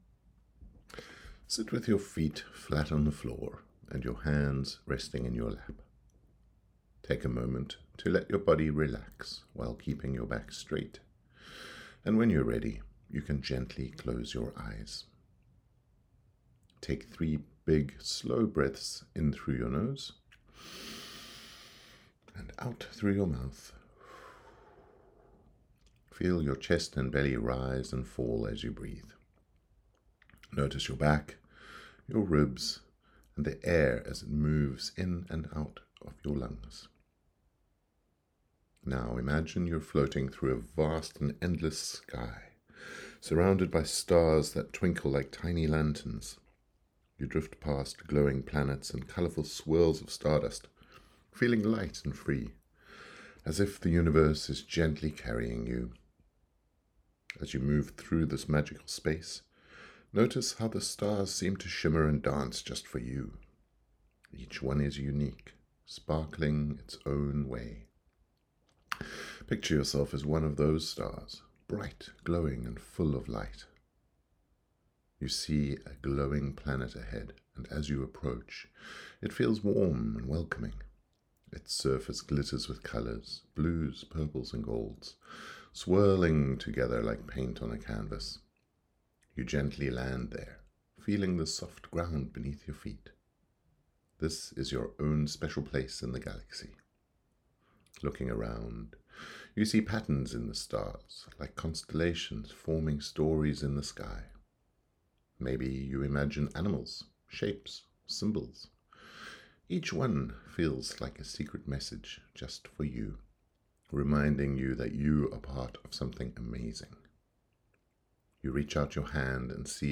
Galaxy Glam Meditation
TP05-meditation.mp3